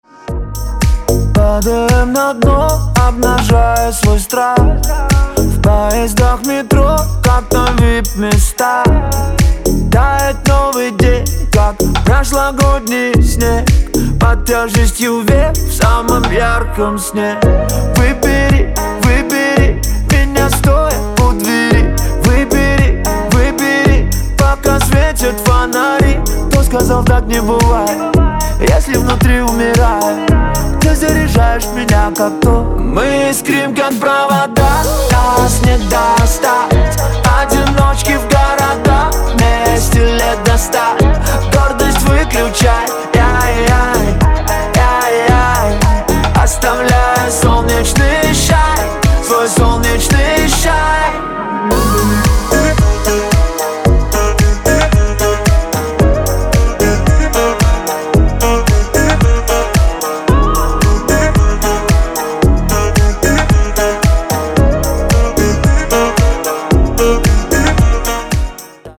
мужской вокал
deep house
dance
Electronic
электронная музыка
спокойные